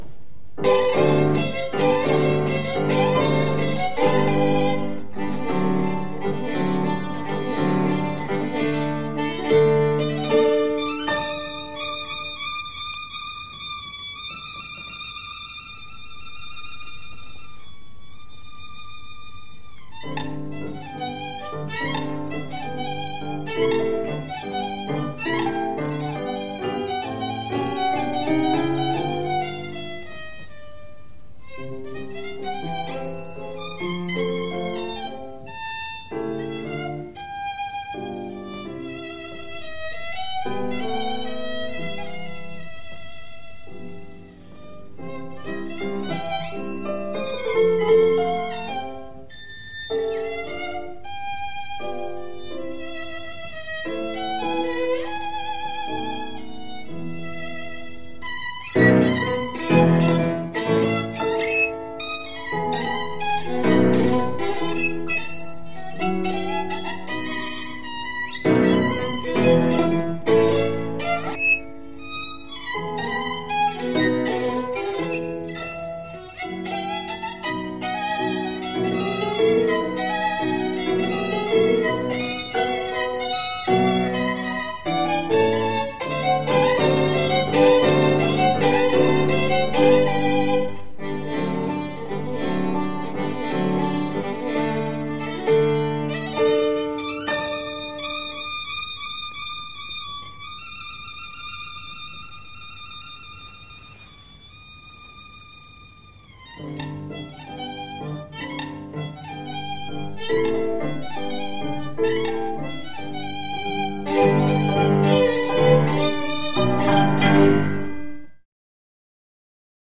Violin
Classical (View more Classical Violin Music)
2-mazurkas-op-19.mp3